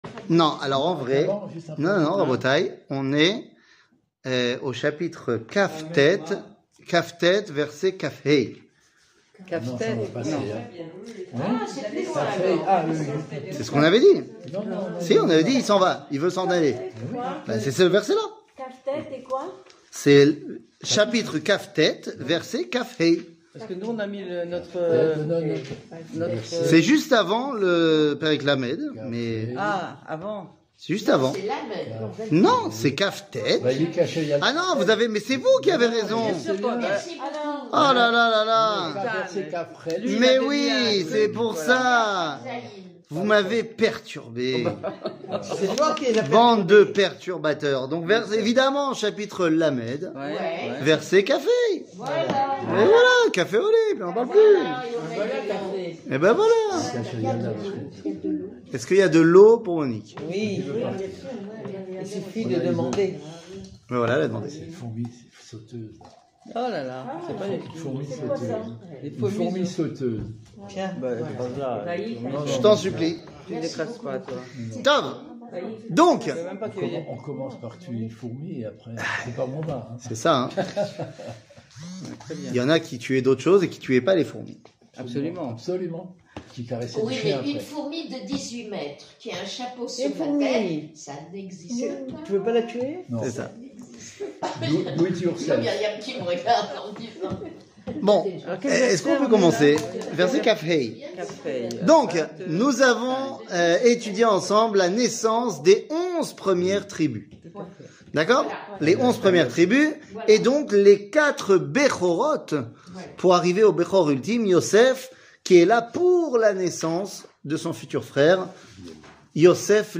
קטגוריה Livre de Berechit 00:53:27 Livre de Berechit שיעור מ 26 אוקטובר 2022 53MIN הורדה בקובץ אודיו MP3